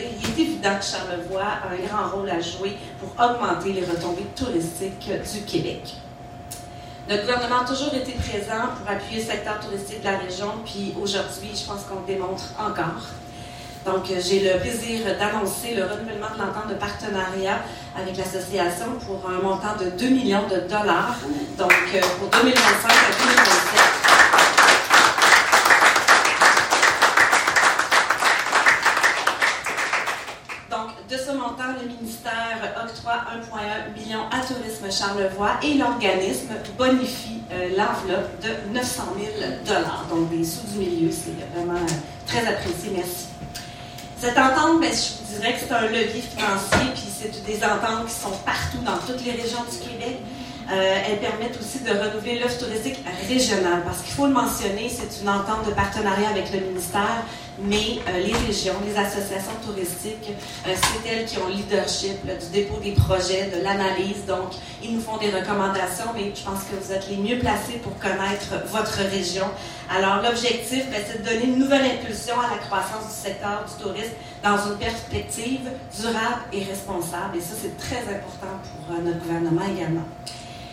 L’annonce a été faite par la ministre du Tourisme, Amélie Dionne, lors d’un point de presse tenu à Clermont, chez Safran Nordique.
L’annonce a été faite par la ministre du Tourisme, Amélie Dionne.